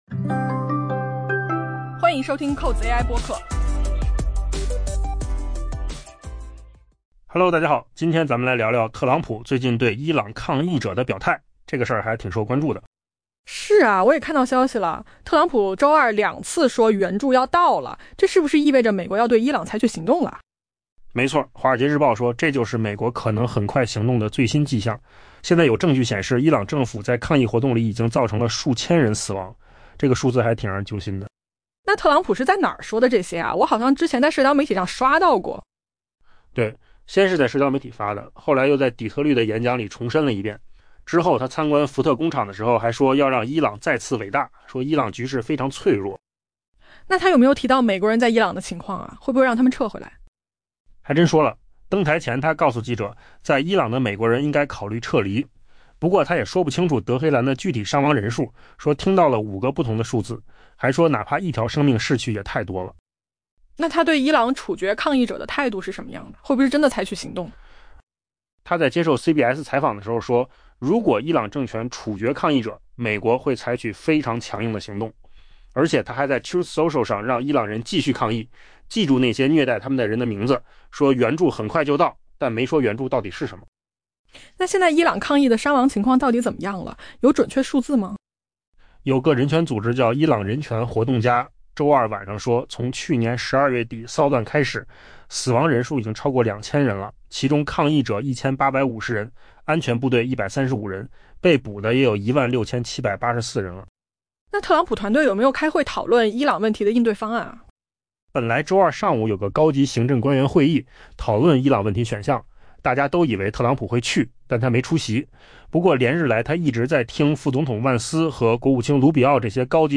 AI播客：换个方式听新闻 下载mp3
音频由扣子空间生成